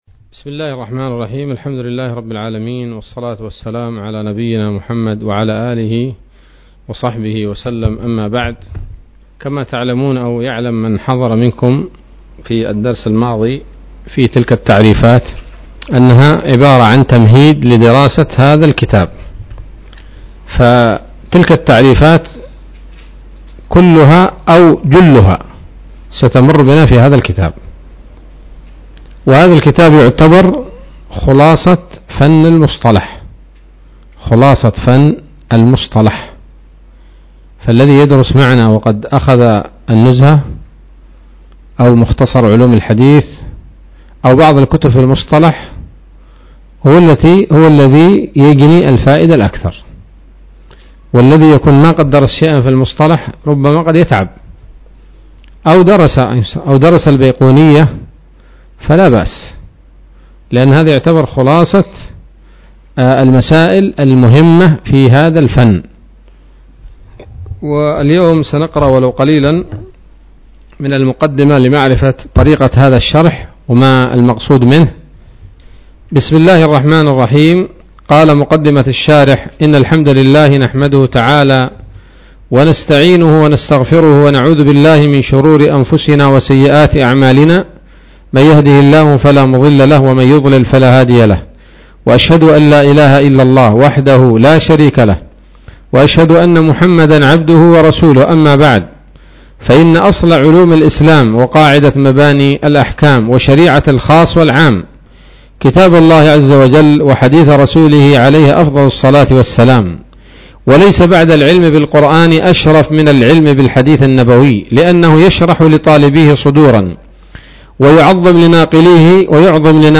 الدرس الأول من المسك والعنبر في شرح قصب السكر